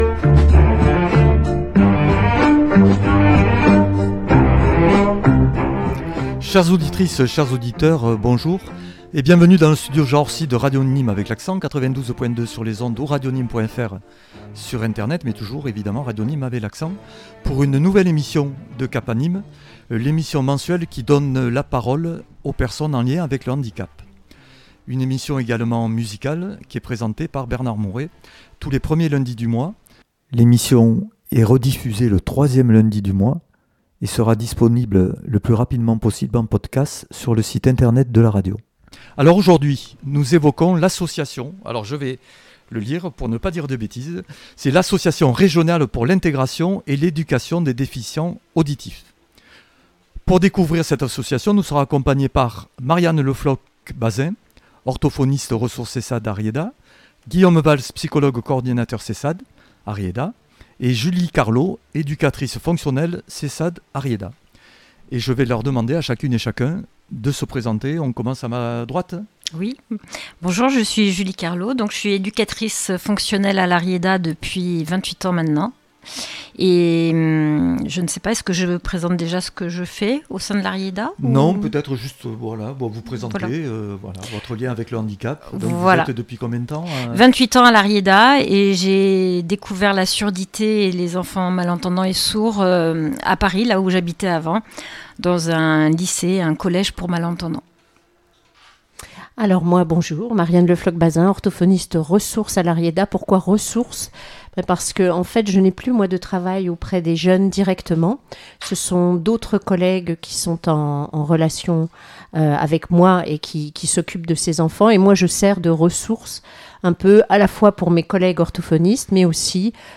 en direct des studios "Jean Orsi"